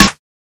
Tuned drums (C key) Free sound effects and audio clips
• 00s Verby Steel Snare Drum Sound C Key 21.wav
Royality free acoustic snare sound tuned to the C note. Loudest frequency: 2641Hz
00s-verby-steel-snare-drum-sound-c-key-21-PSE.wav